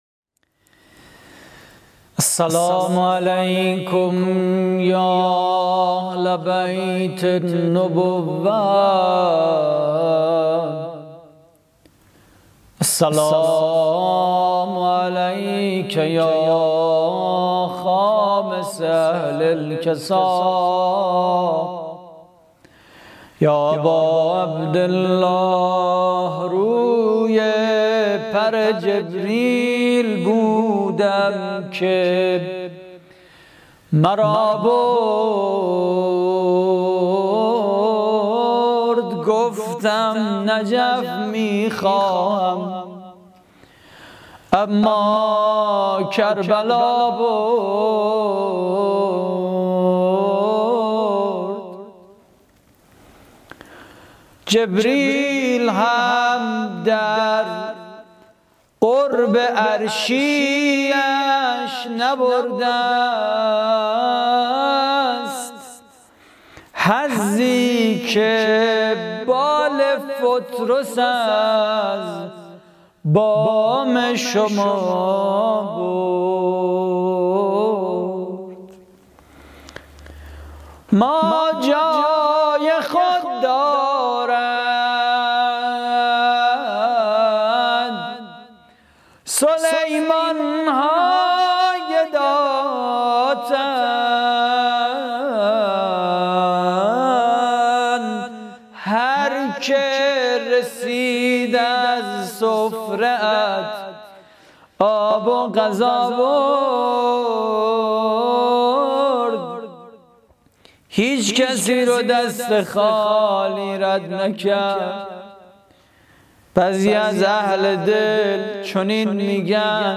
روضه: روی پر جبریل بودم که مرا برد
روضه و مناجات هفتگی